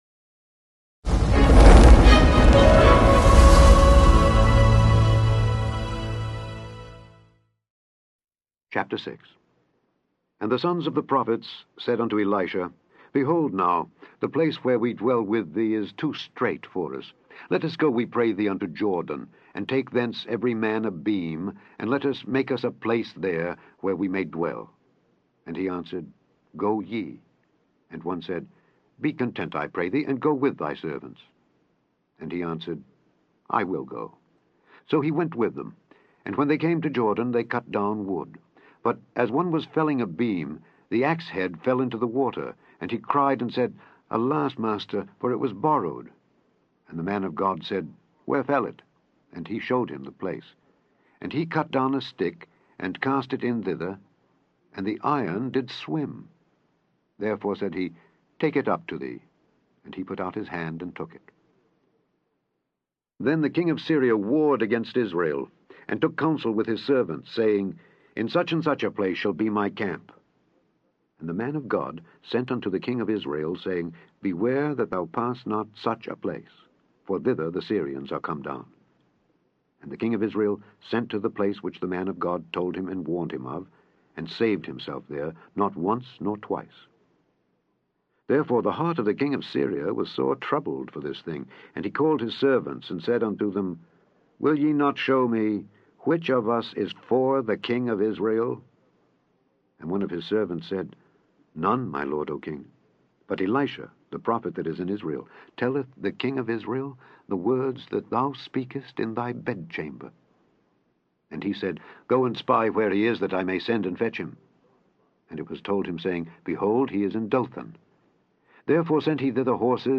Daily Bible Reading: 2 Kings 6-10
In this podcast, you can listen to Alexander Scourby read 2 Kings 6-10.